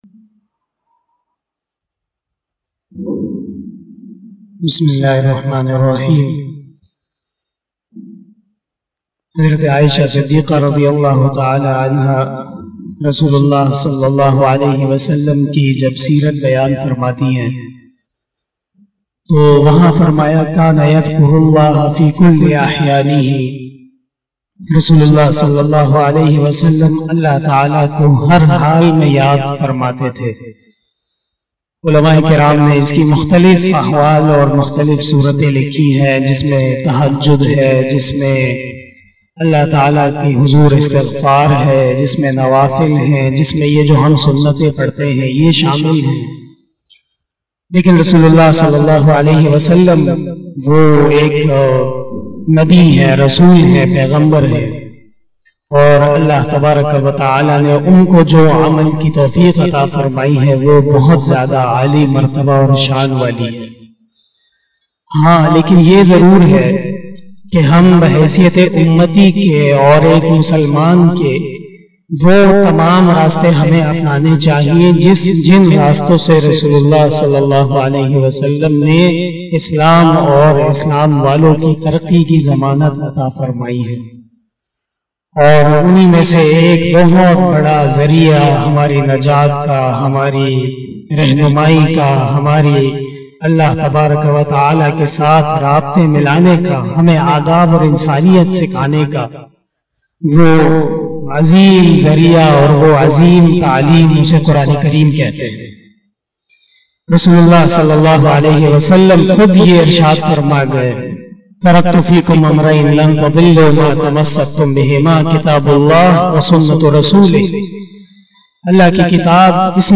059 After Isha Namaz Bayan 28 September 2021 (20 Safar 1443HJ) Tuesday